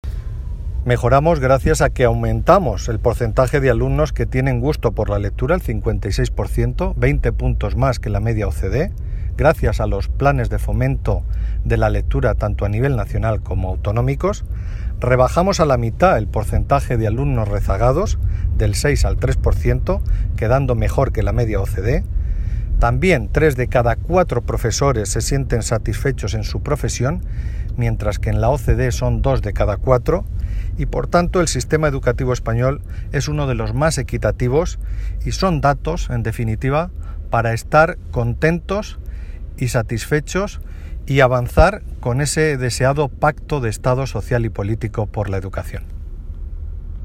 Valoración del secretario de Estado de Educación, Formación Profesional y Universidades, Marcial Marín Audio